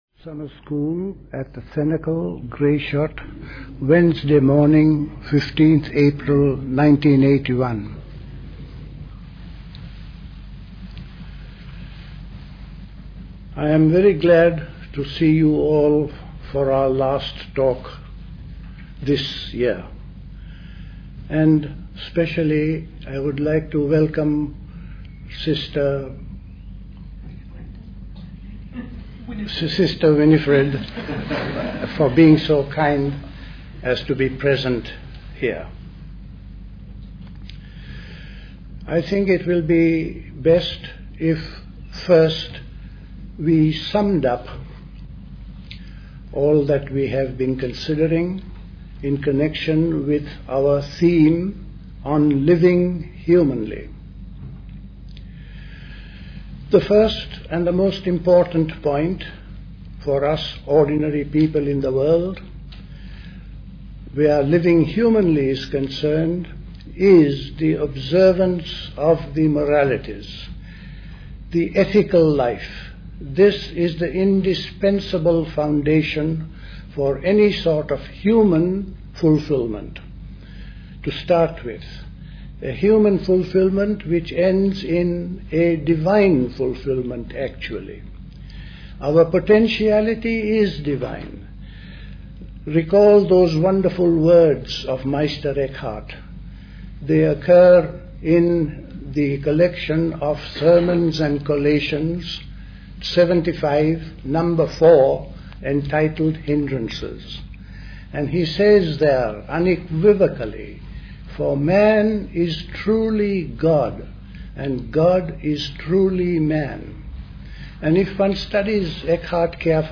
A talk
at the Convent of the Cenacle, Grayshott, Hampshire